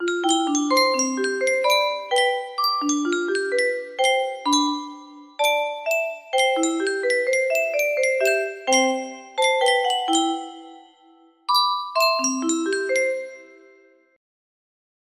Yunsheng Music Box - Unknown Tune 2377 music box melody
Full range 60